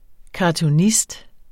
Udtale [ kɑtuˈnisd ]